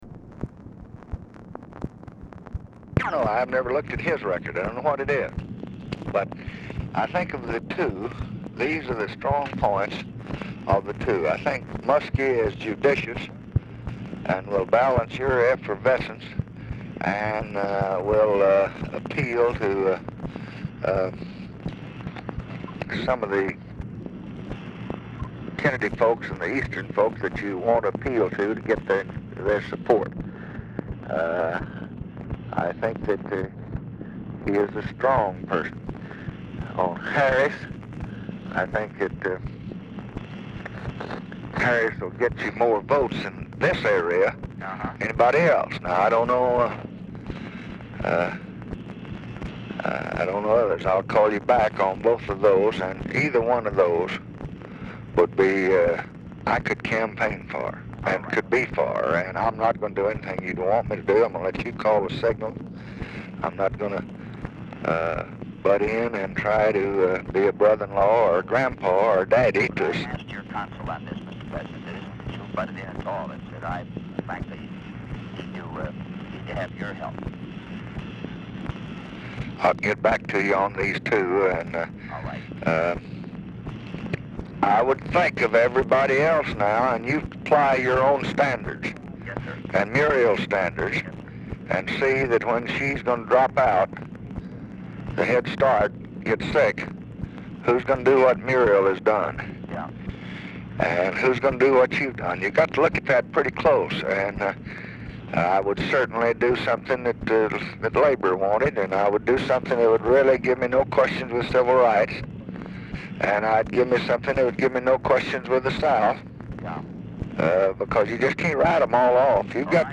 LBJ Ranch, near Stonewall, Texas
Telephone conversation
Dictation belt